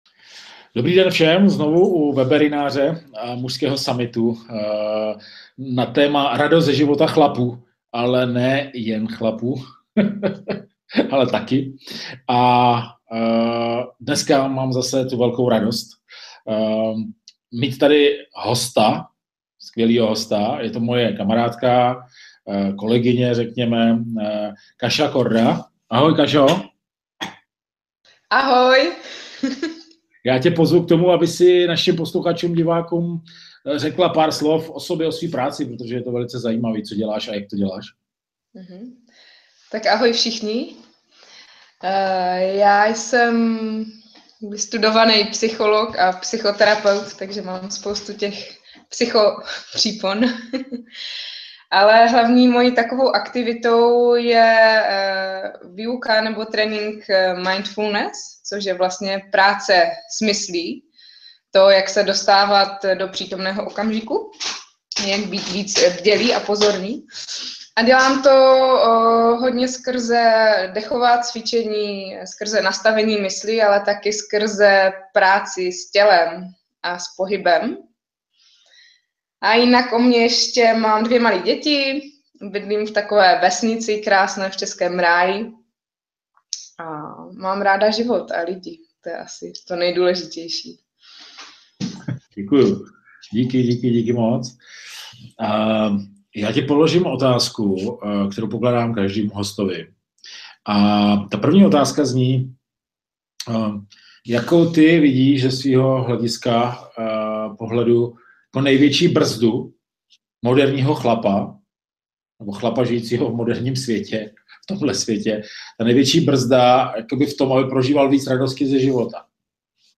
Záznam videa v MP3 Nářez o radosti od ženy